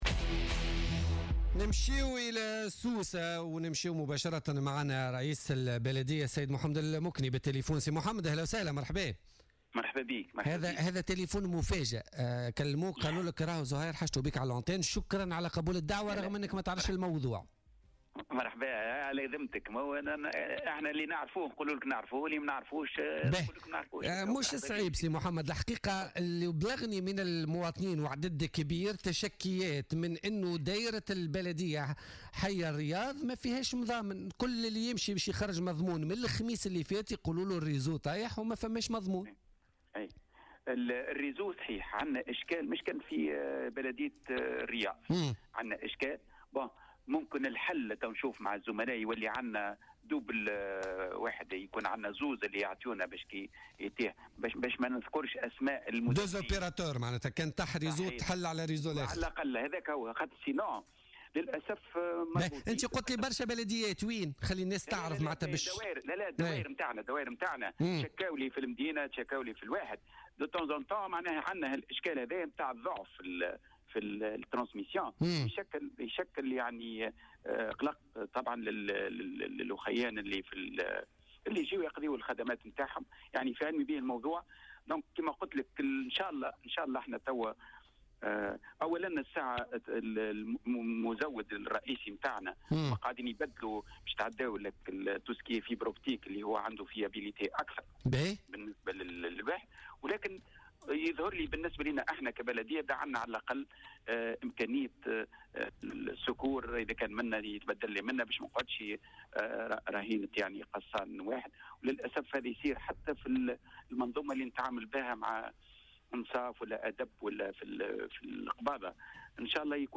وأوضح رئيس بلدية سوسة محمد المكني في مداخلة له في بولتيكا اليوم الثلاثاء 11 أفريل 2017 أن اشكال انقطاع "الأنترنت" موجود بالفعل وتواجهه هذه الأيام جملة من البلديات في سوسة وذلك بسبب أشغال يقوم بها مزودو خدمة الإنترنت .